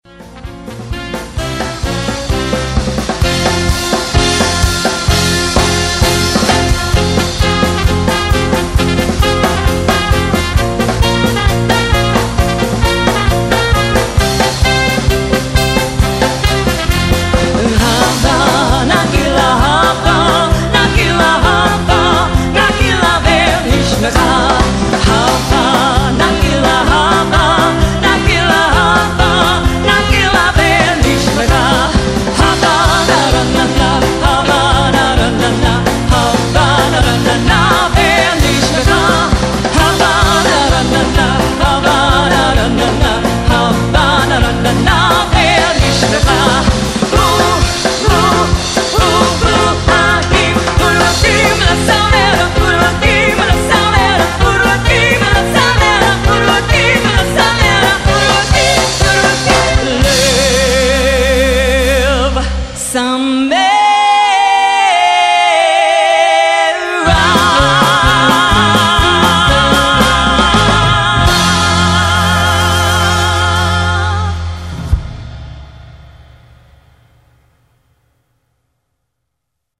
Wedding band soundclips
Jewish